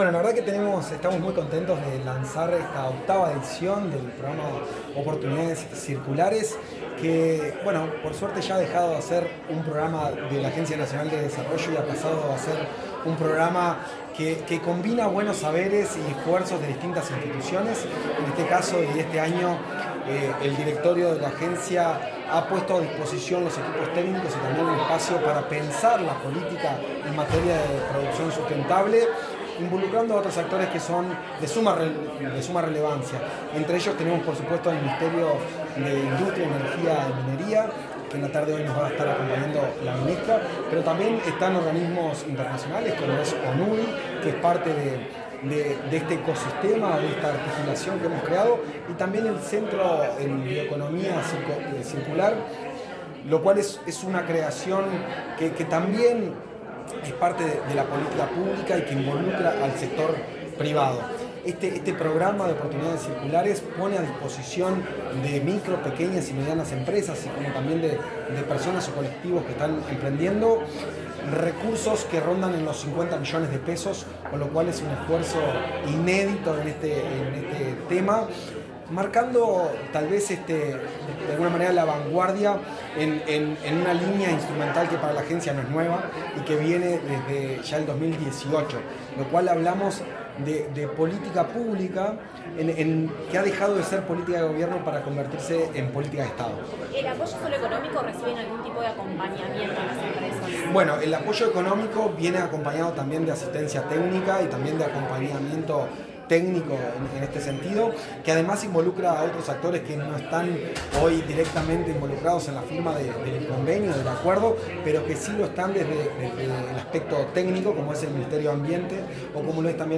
Declaraciones del presidente de ANDE, Juan Ignacio Dorrego
El presidente de la Agencia Nacional de Desarrollo (ANDE), Juan Ignacio Dorrego, dialogó con la prensa tras participar en el lanzamiento del programa